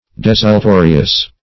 Desultorious \Des`ul*to"ri*ous\